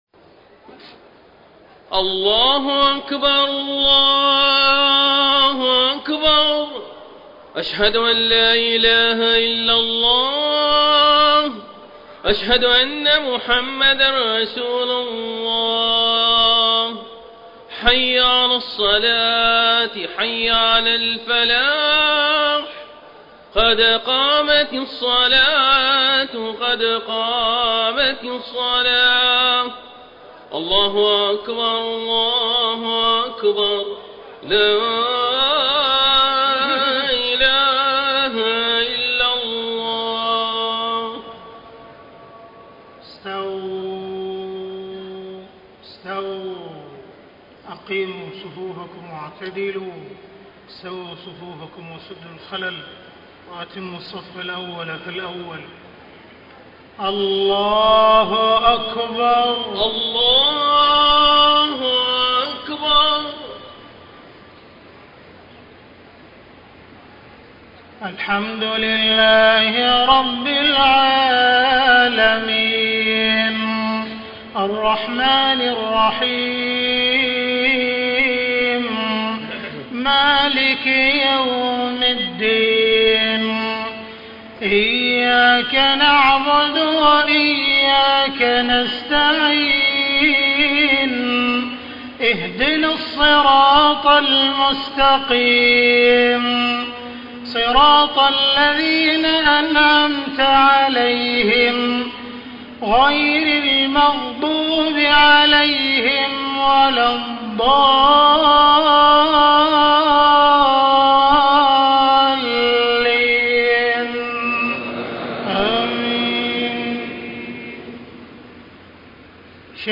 صلاة المغرب 1-9-1434 من سورة البقرة > 1434 🕋 > الفروض - تلاوات الحرمين